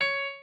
piano11_11.ogg